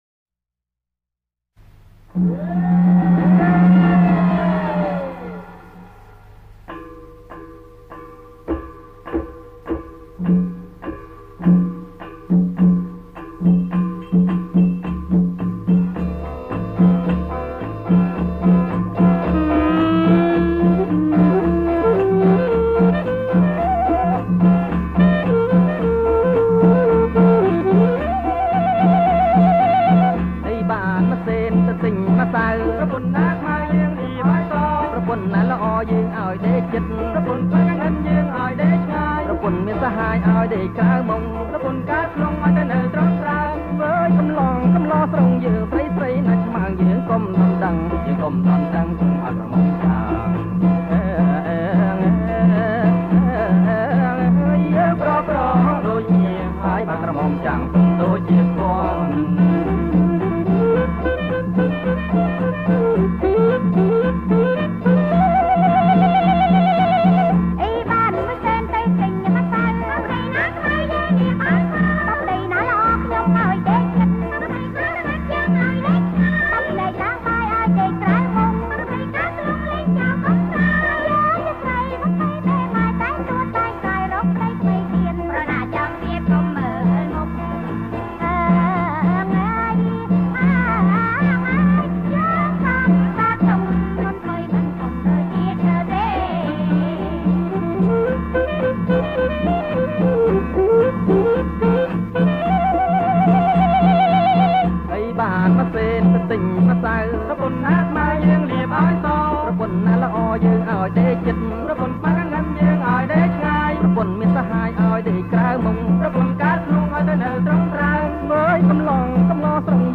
ប្រគំជាចង្វាក់ ឡាំលាវ